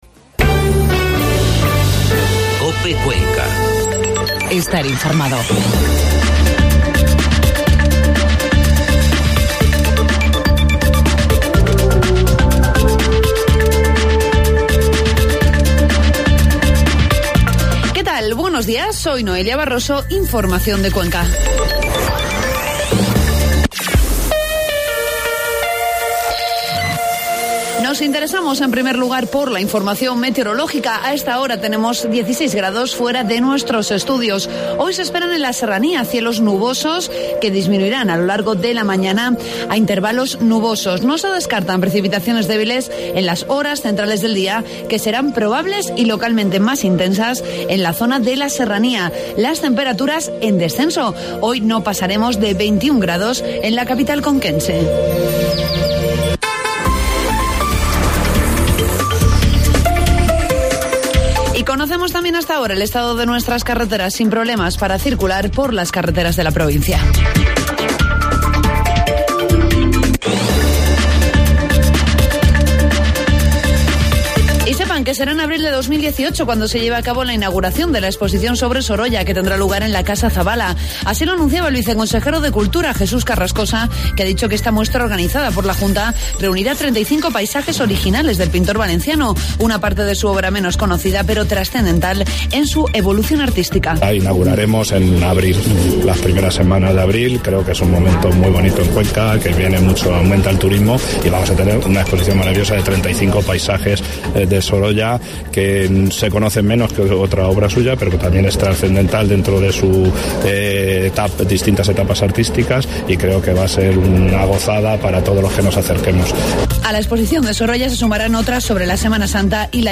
Informativo matinal COPE Cuenca 15 de septiembre
AUDIO: Informativo matinal